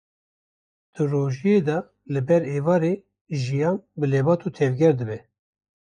Read more Verb Noun Pronounced as (IPA) /ʒɪˈjɑːn/ Etymology From Proto-Iranian *ǰíHwati, from Proto-Indo-European *gʷeih₃w- (“to live”).